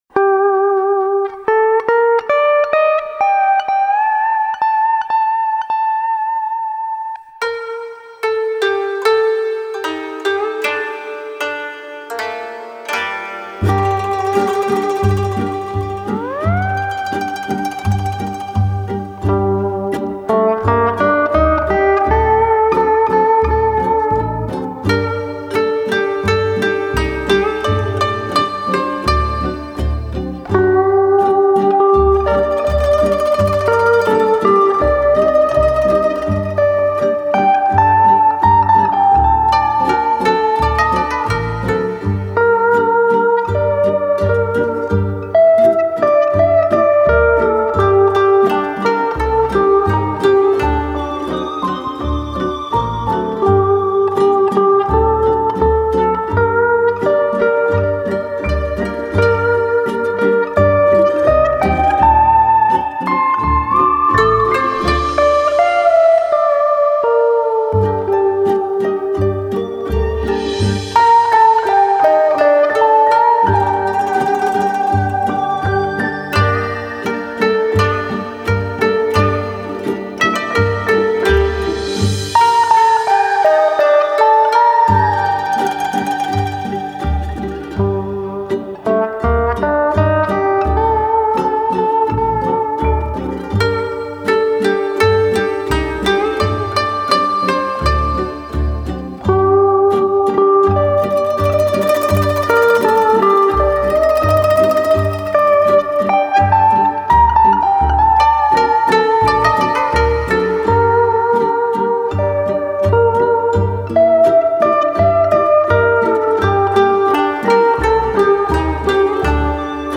Genre: Jazz,Latin